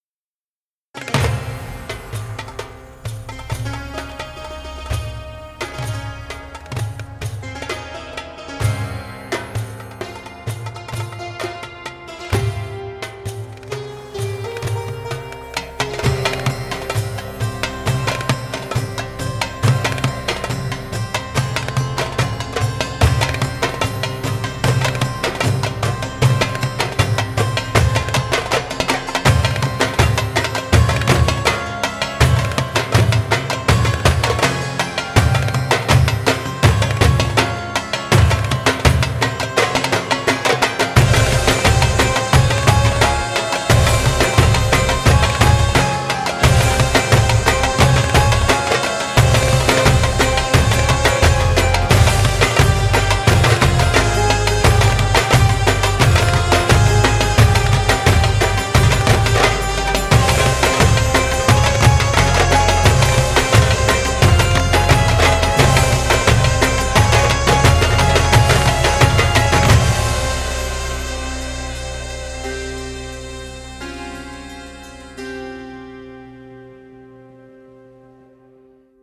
它适合用于制作电影配乐和中东风格的音乐。
- 三种不同的录音模式：单人、三人和六人合奏
- 多种不同大小的Darbuka乐器可供选择